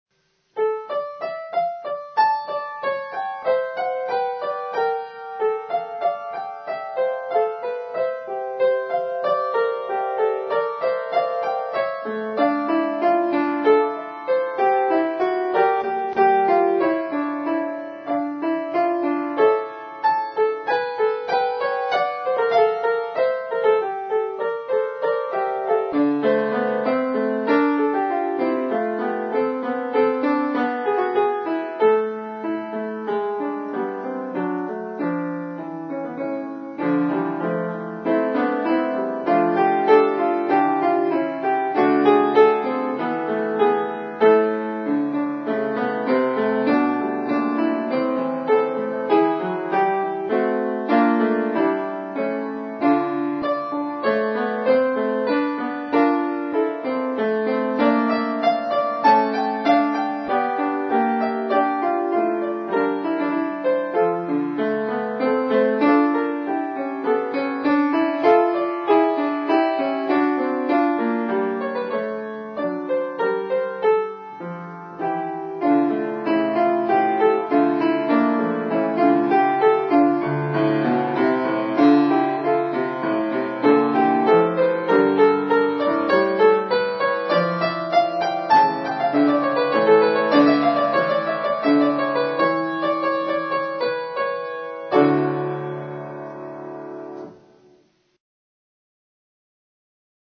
Piano Recital: